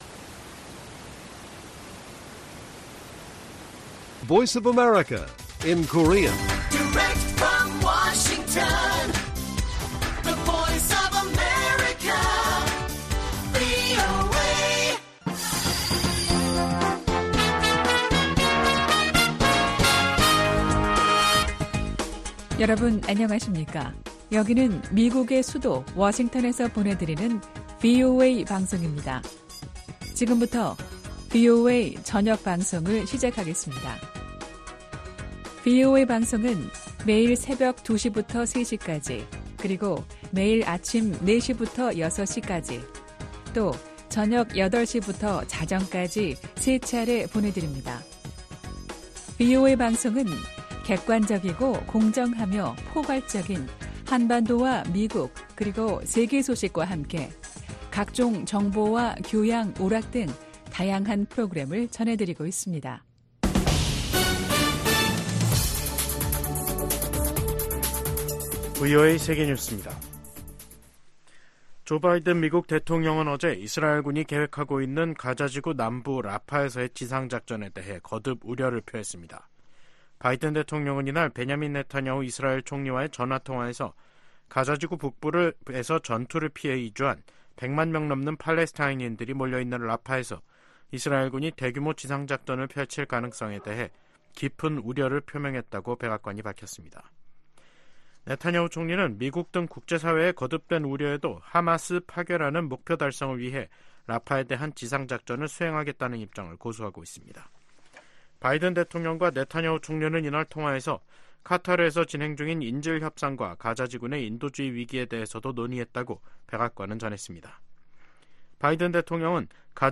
VOA 한국어 간판 뉴스 프로그램 '뉴스 투데이', 2024년 3월 19일 1부 방송입니다. 린다 토머스-그린필드 유엔 주재 미국 대사가 북한의 17일 단거리 탄도미사일 발사를 비판했습니다. 김정은 북한 국무위원장은 18일 한국 수도권 등을 겨냥한 초대형 방사포 사격훈련을 지도하며 위협 수위를 높였습니다. 미국 대선과 한국 총선을 앞둔 올해 북한의 임박한 공격 징후는 보이지 않는다고 정 박 미 국무부 대북 고위관리가 말했습니다.